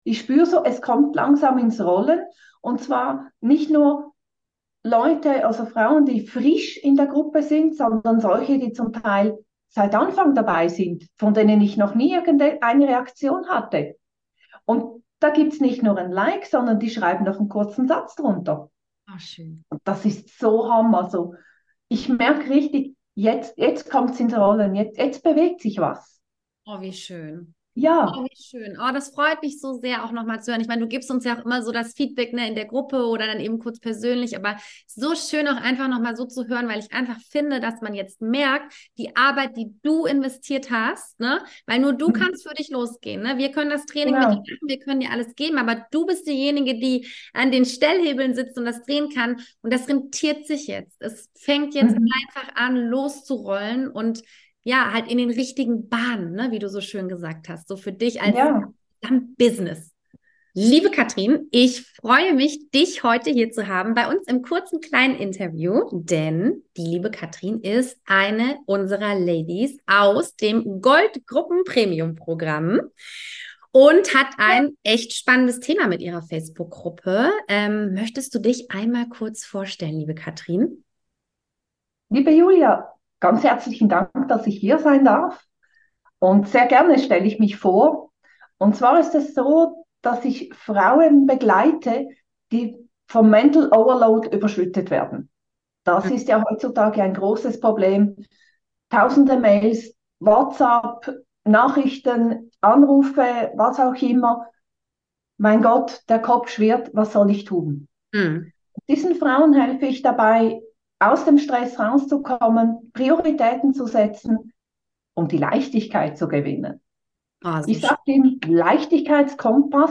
Erfolgsinterview